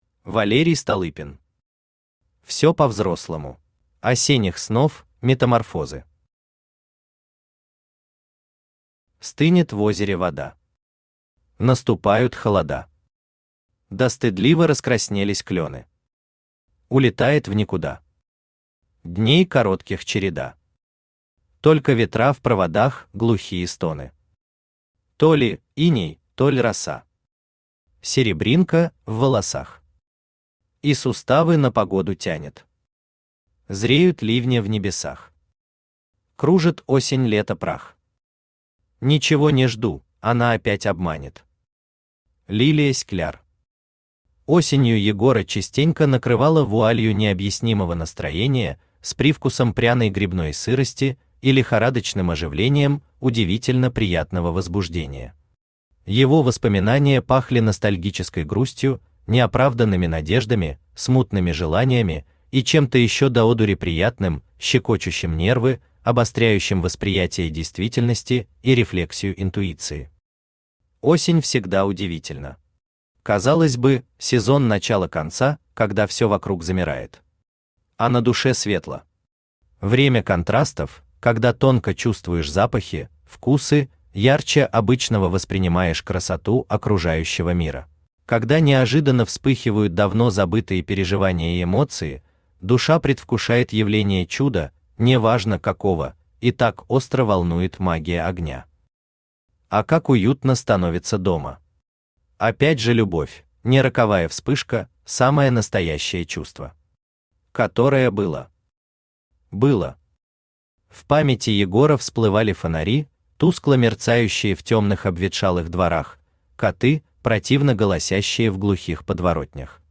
Аудиокнига Всё по-взрослому | Библиотека аудиокниг
Aудиокнига Всё по-взрослому Автор Валерий Столыпин Читает аудиокнигу Авточтец ЛитРес.